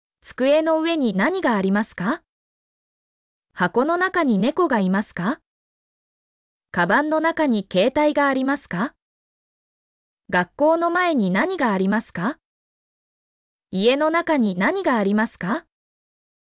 mp3-output-ttsfreedotcom-8_mV6hNoex.mp3